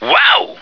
flak_m/sounds/male1/est/M1ohyeah.ogg at efc08c3d1633b478afbfe5c214bbab017949b51b
M1ohyeah.ogg